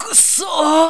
越前がダメージを受けた時の叫び声の一つ。